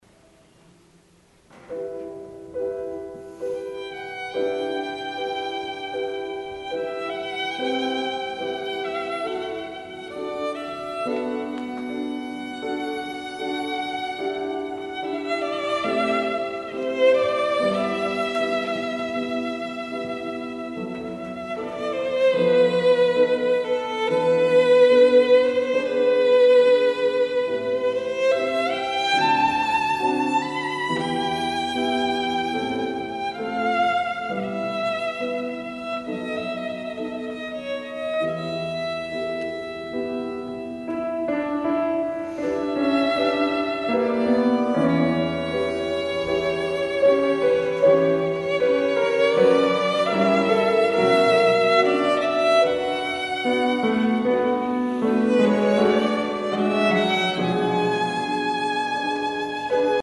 This is the live record. Sorry for some ambiance noises.